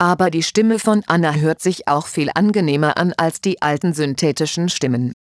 Text To Speech.
Nein da kann ich Dir nicht helfen, da ich nur die Systemstimme von meinem Mac verwende und der hat "nur" die modernen fast natürlich klingenden Stimmen wie Anna und Markus .
Ich habe extra mal nachgeschaut da man auch andere Stimmen downloaden kann aber keine altertümliche Roboterstimme war dabei ;)
AberdieStimmevonAnna_A.wav